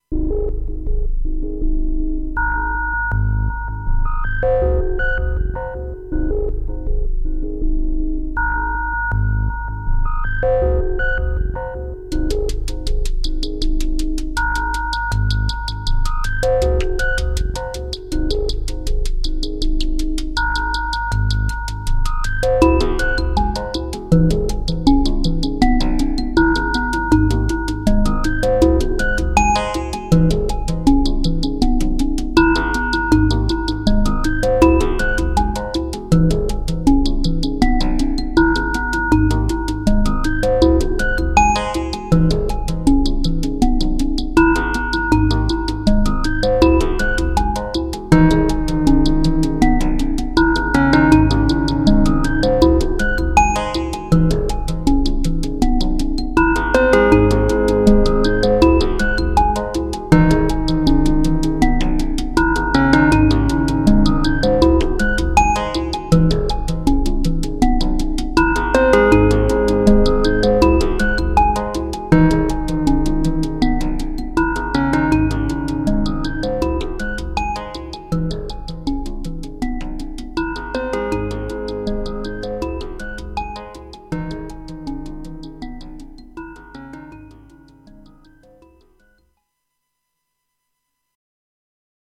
Received mine today. Spent a bit of time with presets and the manual and now…here’s my first pattern…if it interests anyone :wink:
(the first few bars you hear are just one track with recorded and plocked changes - very cool)
Just some EQ in Reaper but to be honest the MP3 conversion has stripped a lot of loveliness I can hear in the actual recording.